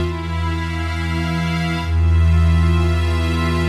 Index of /musicradar/80s-heat-samples/130bpm
AM_80sOrch_130-E.wav